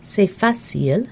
Click each phrase to hear the pronunciation.